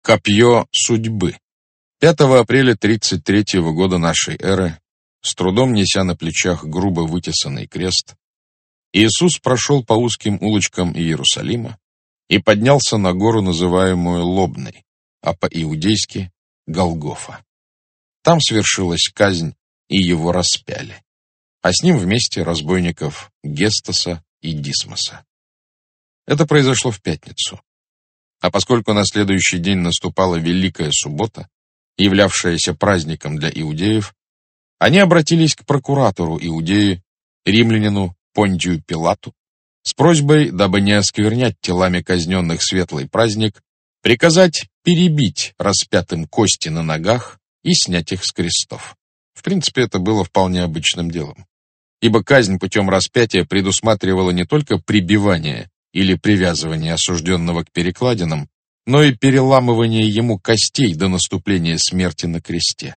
Аудиокнига Секреты III рейха | Библиотека аудиокниг
Aудиокнига Секреты III рейха Автор Сборник Читает аудиокнигу Александр Клюквин.